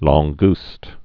(läɴ-gst)